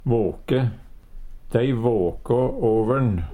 DIALEKTORD PÅ NORMERT NORSK våke vake Infinitiv Presens Preteritum Perfektum våke våkå våkå våkå Eksempel på bruk Dei våkå åver`n. Hør på dette ordet Ordklasse: Verb Kategori: Kropp, helse, slekt (mennesket) Attende til søk